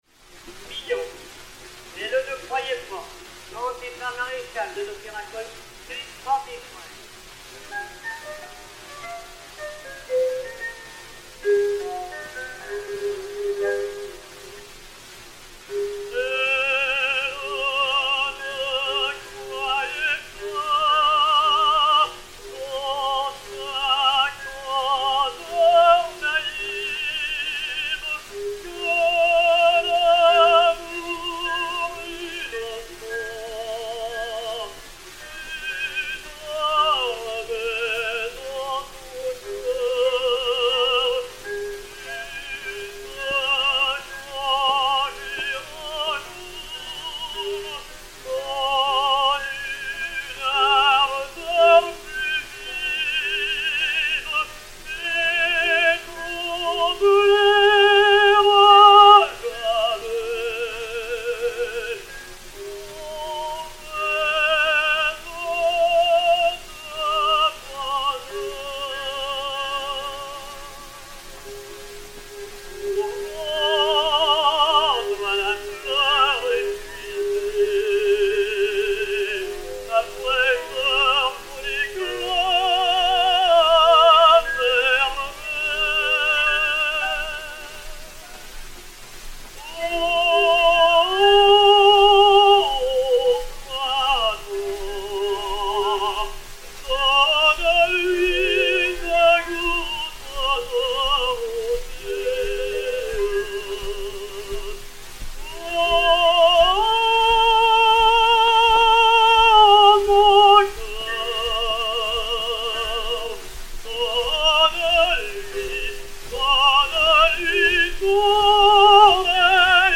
Le ténor Maréchal, une des plus jolies voix que j'aie entendues, d'un métal riche, éclatant dans l'aigu et doux en même temps, avec quelque chose d'un peu mélancolique qui lui donnait un grand charme.
Romance "Elle ne croyait pas"
Adolphe Maréchal (Wilhelm) et Piano
Pathé saphir 90 tours n° 324, enr. en 1904